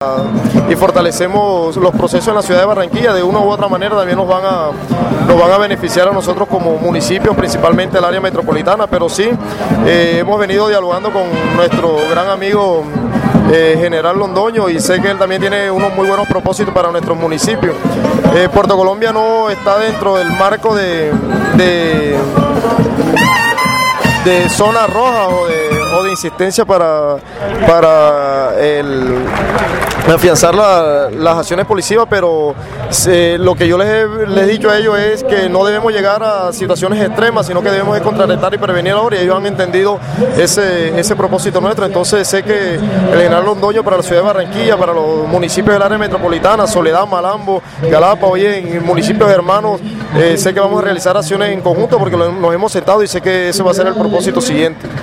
La afirmación la hizo el burgomaestre porteño en el acto inaugural de las obras del estadio Lulio González, confirmando que se han venido reuniendo con el comandante de la Policía Metropolitana, el general Gonzalo Londoño, con quien han hecho equipo, no solamente para acciones de control, sino también de prevención a la inseguridad en esas poblaciones.